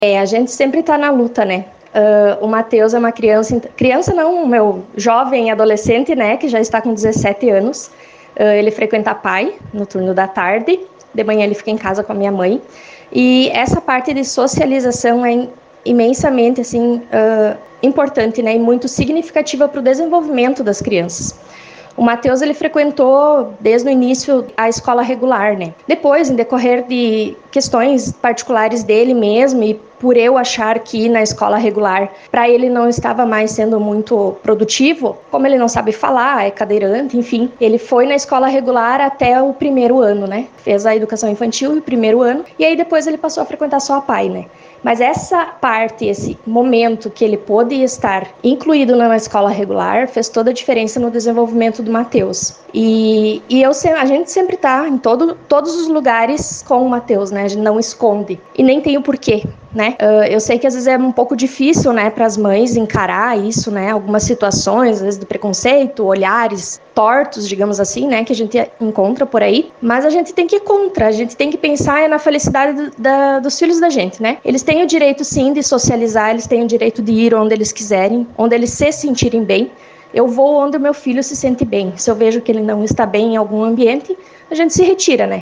comentou em entrevista ao Grupo Ceres as atividades que serão realizadas.